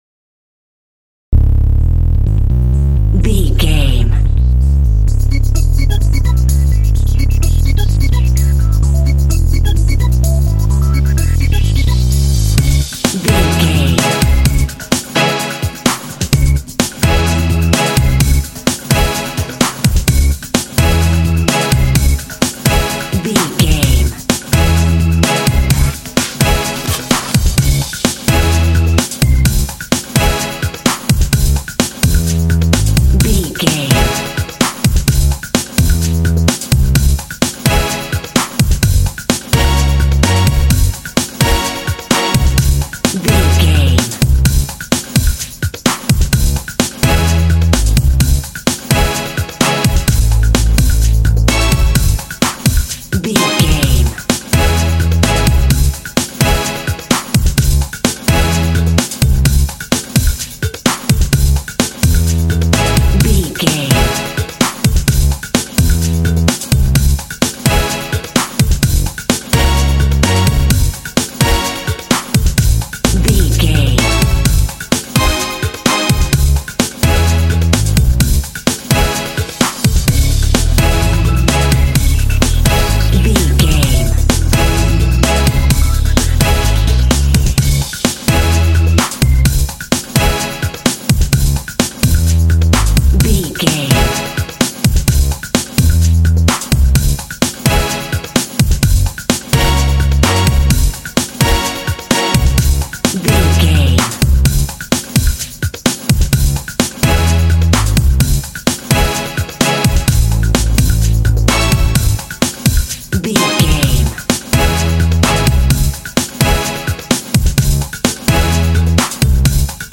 Epic / Action
Aeolian/Minor
C#
funky
happy
bouncy
groovy
synthesiser
drums
electric organ
percussion
strings
r& b